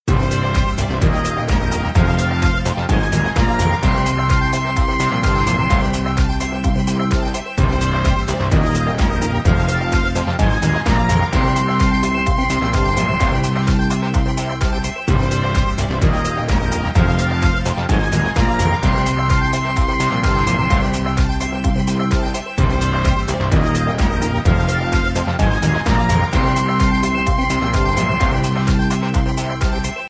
Победный звук
Музыка после боя